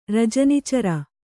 ♪ rajani cara